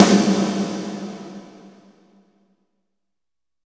12 WET SNR-R.wav